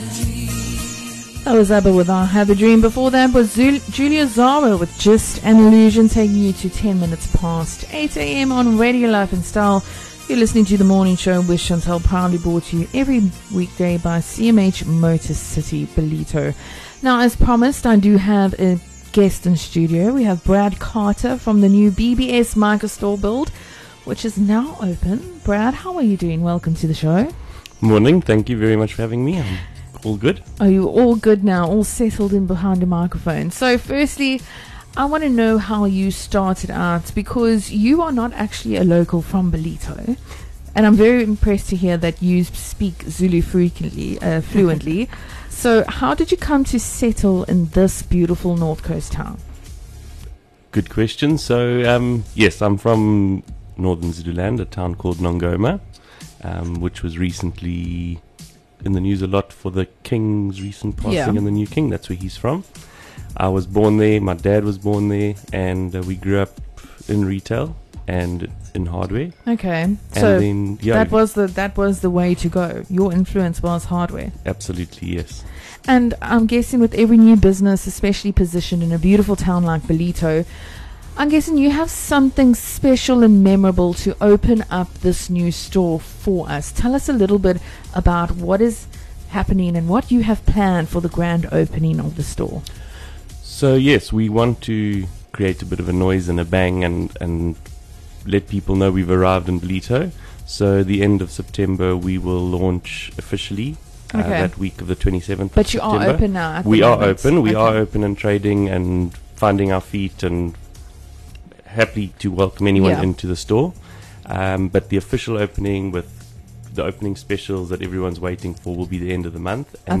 20 Sep BBS Mica New Ballito store opening Interview